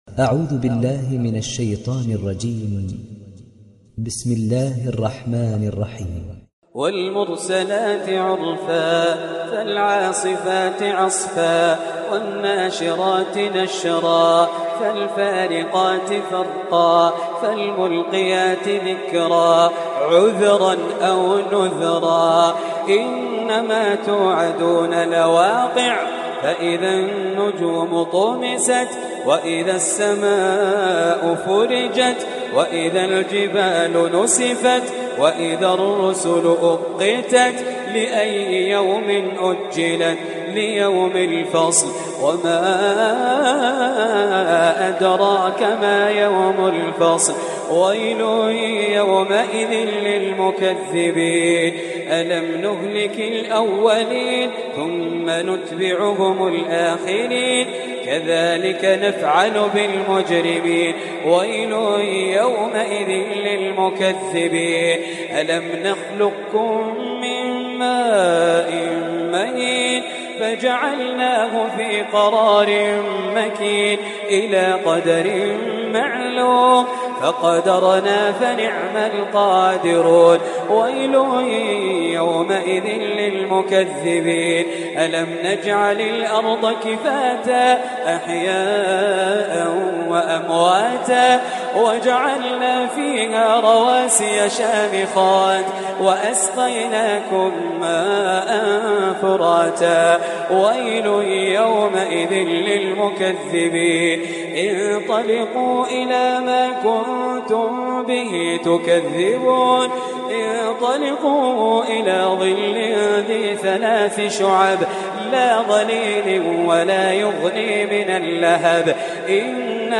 Riwayat Hafs an Assim